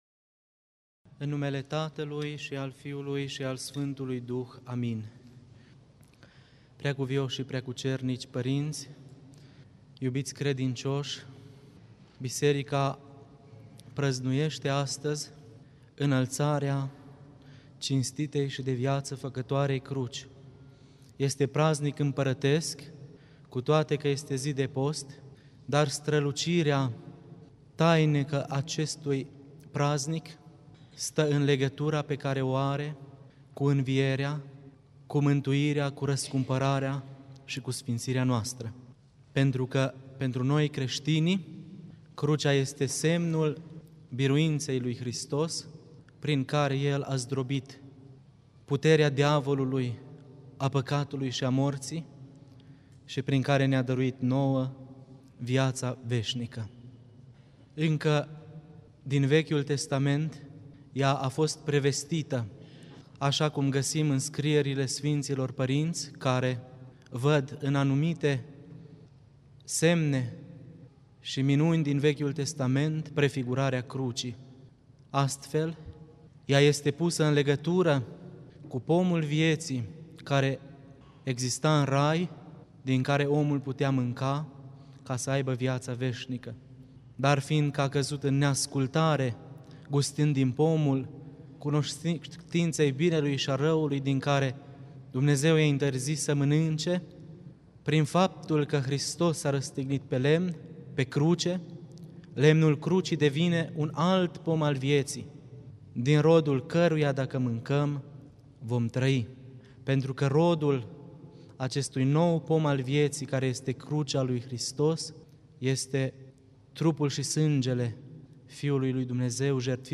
Predică la sărbătoarea Înălțării Sfintei Cruci